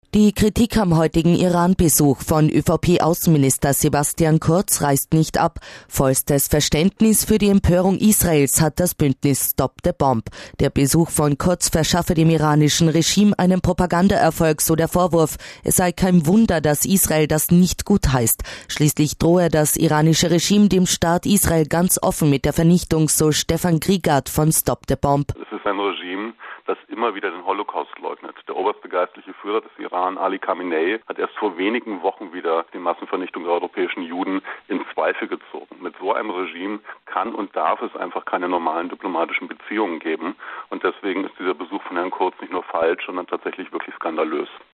KRONE HIT (Radio): Kritik an Iran-Reise von Außenminister Kurz & Interview